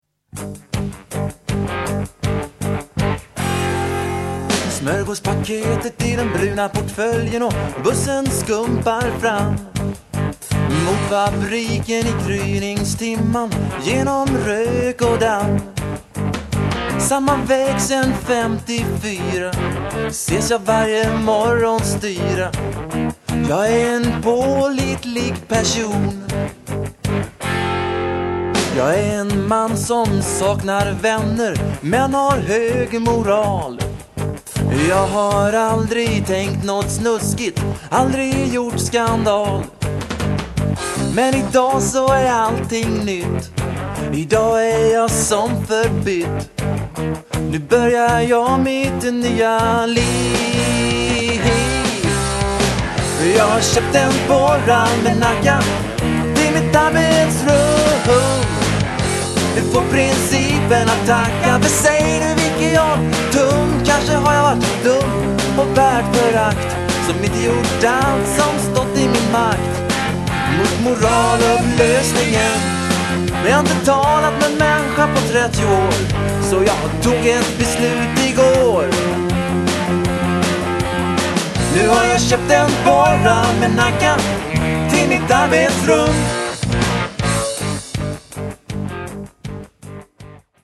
Bortsett från det taffliga framförande (speciellt mina sånginsatser) och vissa lite väl fåniga saker tycker jag det här är en rätt trevlig och enhetlig samling låtar.